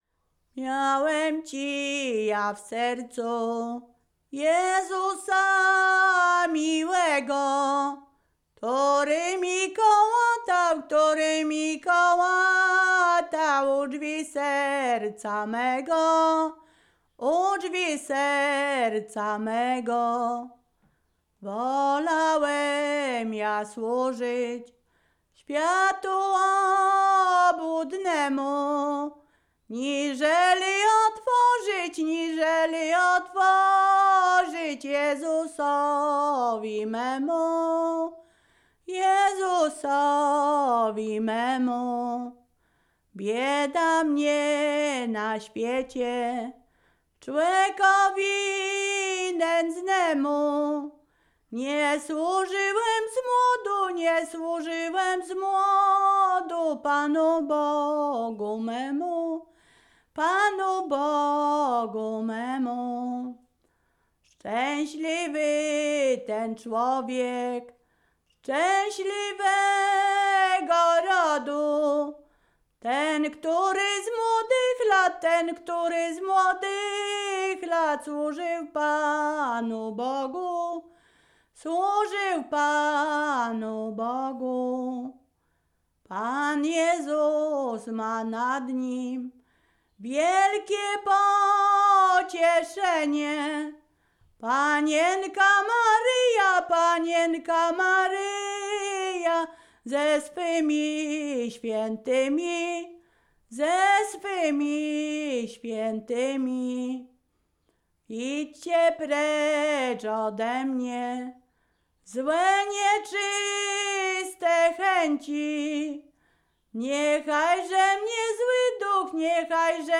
Ziemia Radomska
Pogrzebowa
pogrzebowe nabożne katolickie do grobu